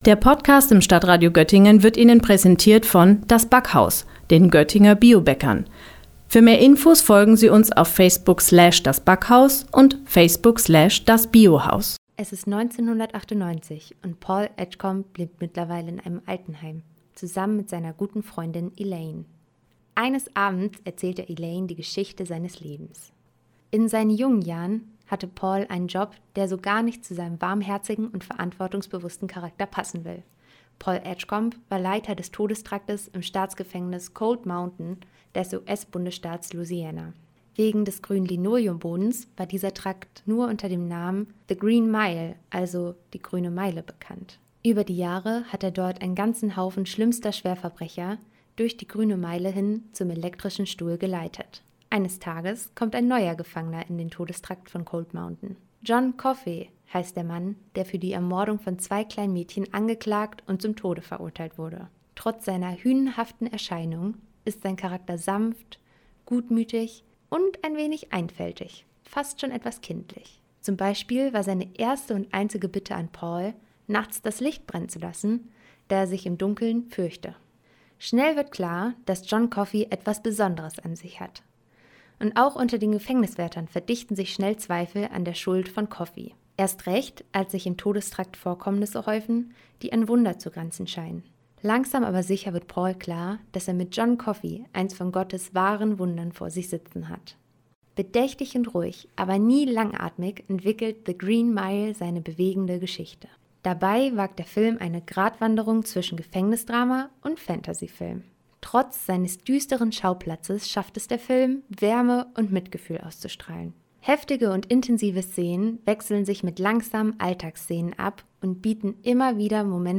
Beiträge > Rezension zu "The Green Mile" - Ein Plädoyer gegen die Todesstrafe - StadtRadio Göttingen